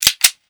GUN ARMS 1-R.wav